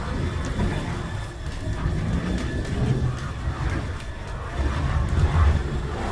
prop_dragging.wav